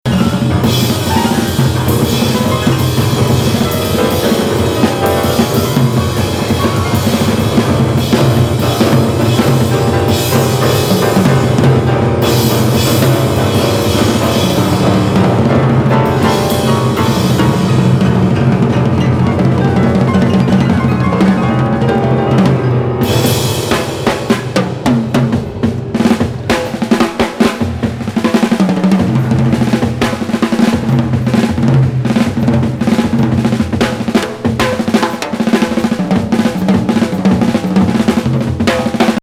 アグレッシブなフリー・インプロの嵐！